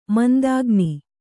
♪ mandāgni